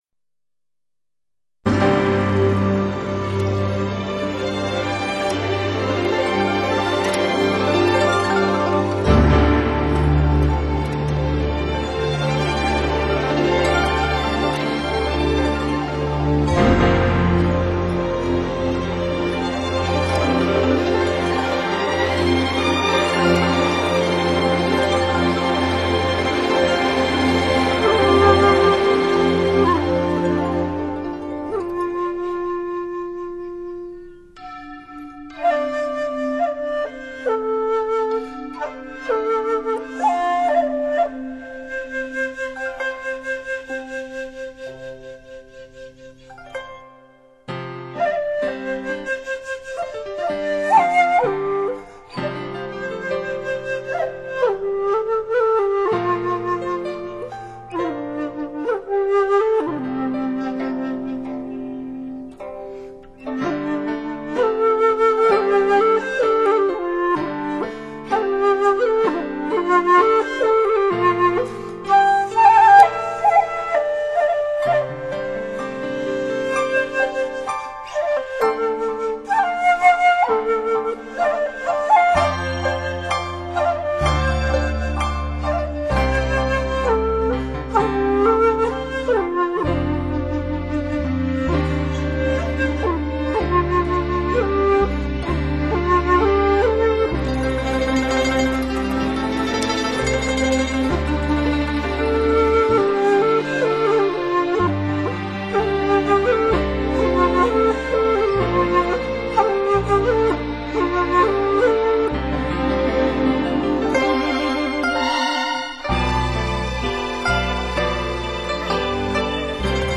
音乐类型：民乐
在短小的引子之后，主题呈现，旋律深沉苍劲，柔中带刚并富于感叹。